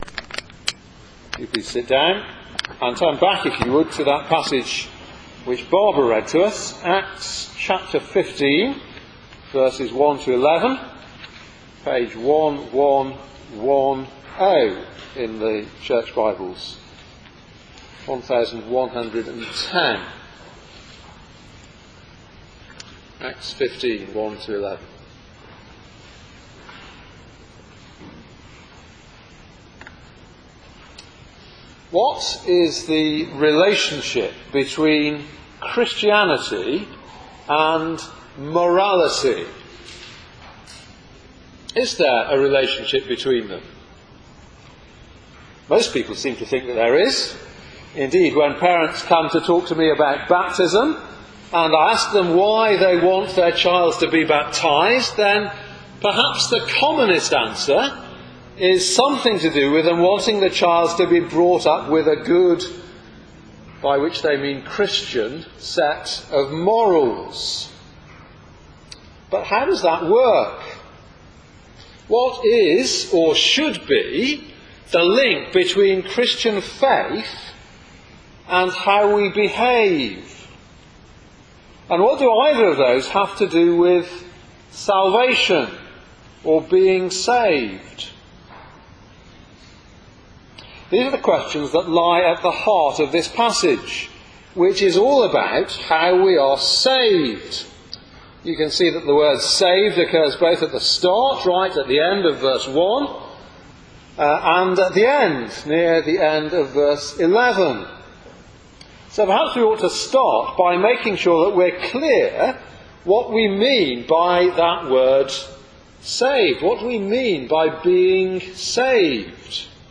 Theme: Sermon